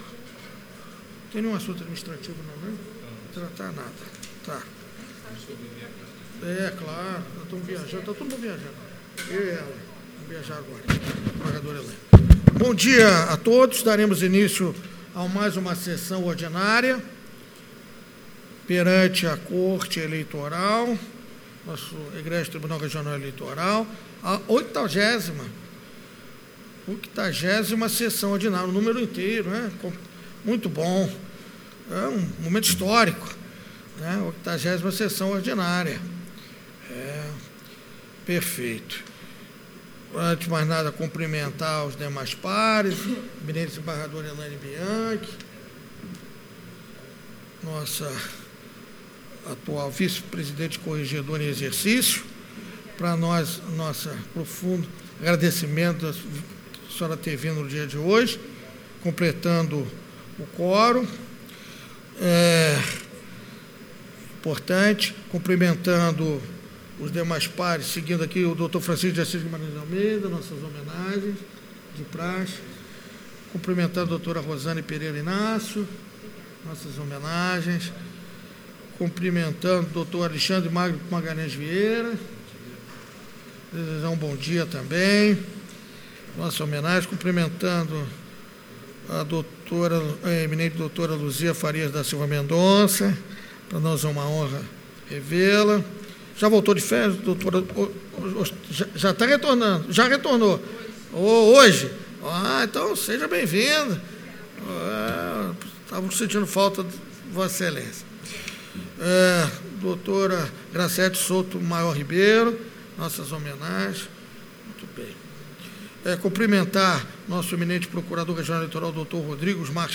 ÁUDIO DA 80.ª SESSÃO ORDINÁRIA, DE 06 DE NOVEMBRO DE 2019